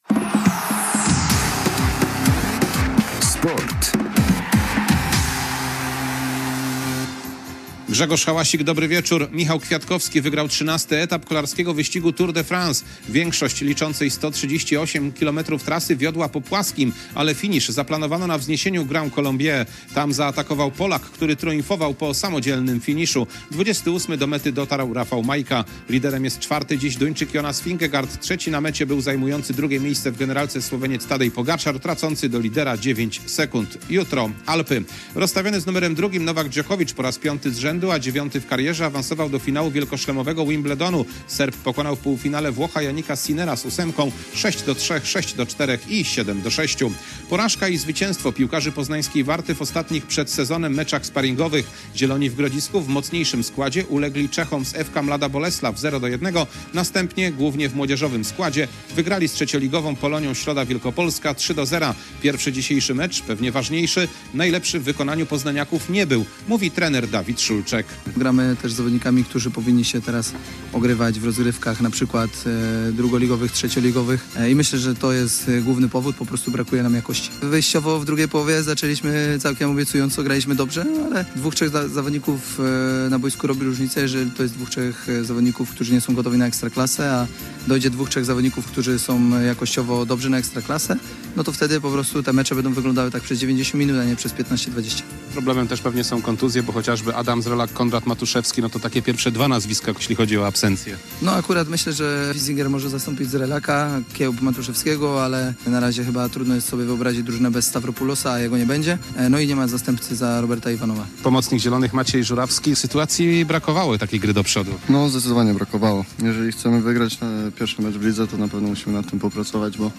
14.07.2023 SERWIS SPORTOWY GODZ. 19:05